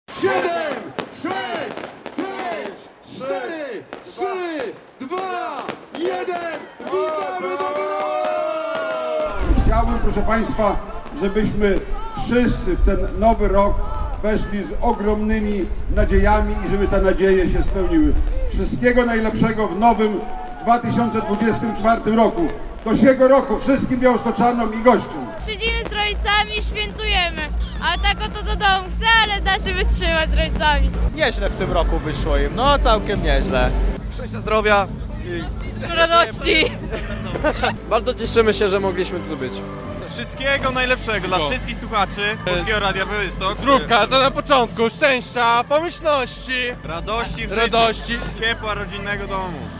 Miejski sylwester w Białymstoku - relacja